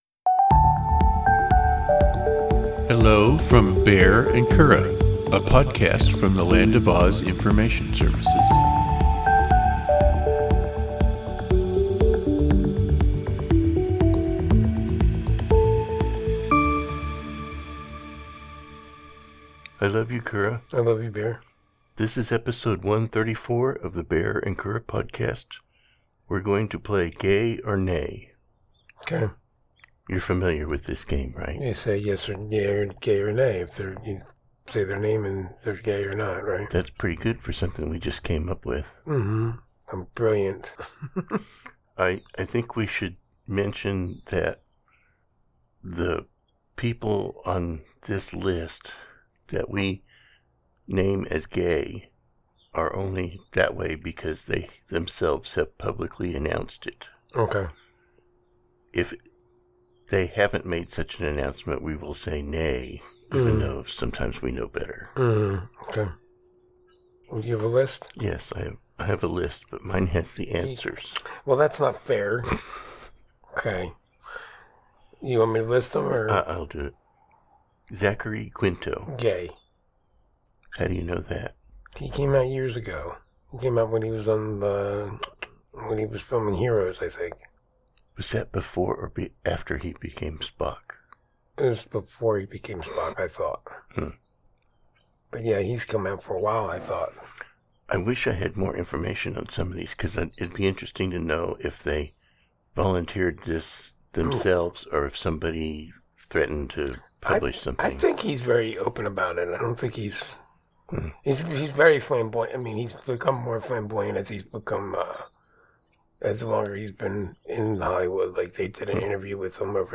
Two married gay guys discuss life, synergy, and the pursuit of happiness.